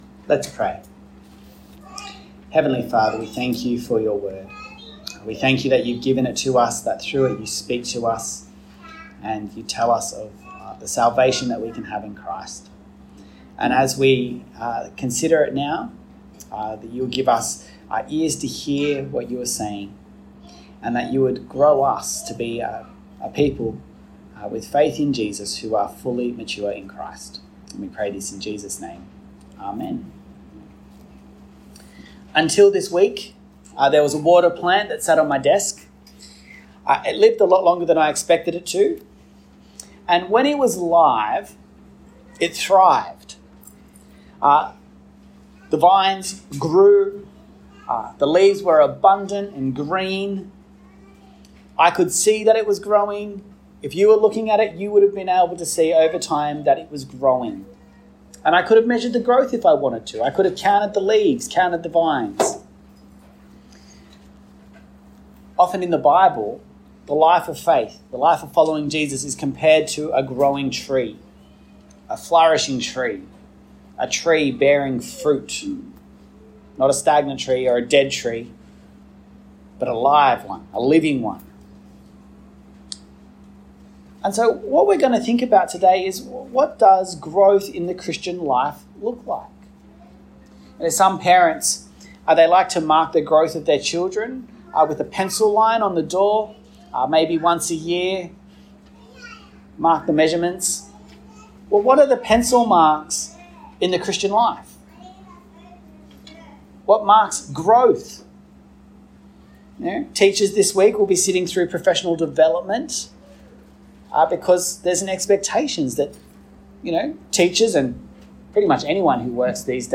From Series: "One Off Sermons"